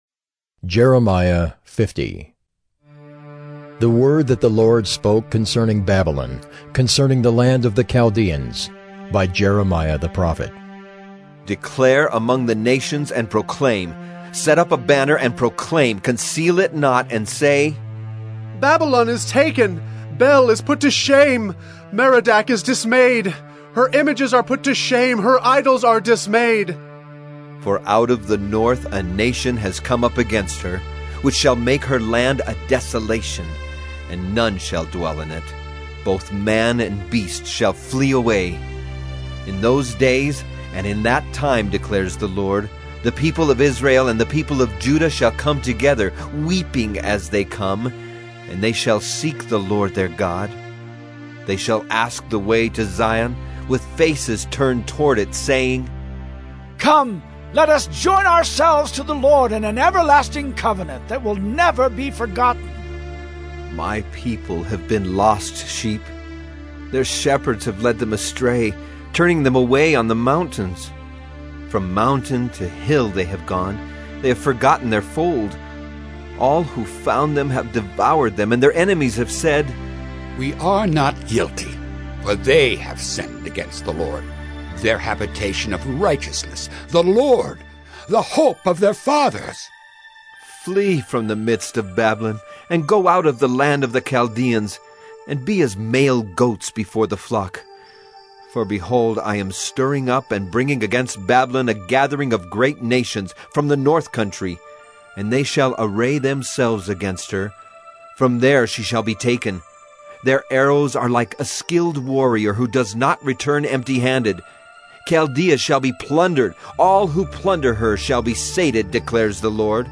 “Listening to God” Bible Reading & Devotion: Feb 10, 2026 – Jeremiah 050